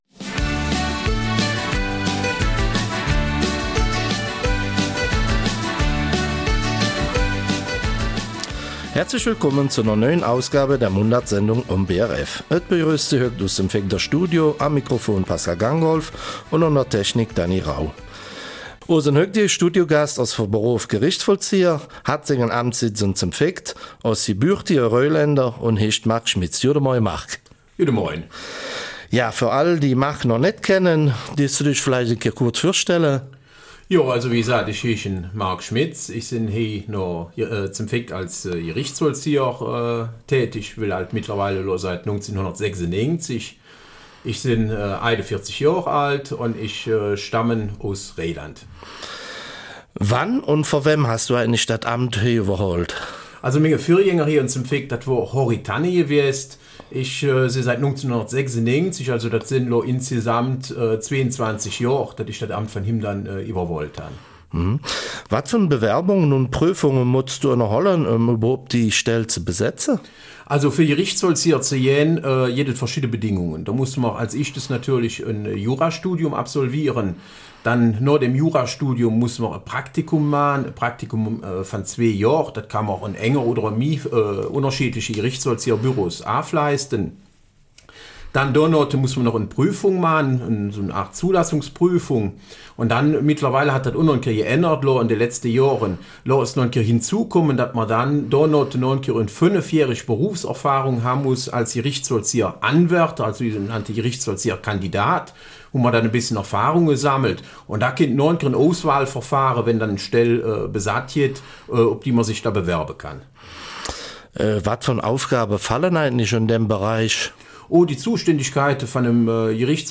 Eifeler Mundart: Gerichtsvollzieher und Weltenbummler